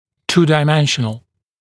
[ˌtuːdaɪ’menʃənl][ˌту:дай’мэншэнл]двухмерный